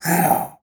animal
Ocelot Hiss 2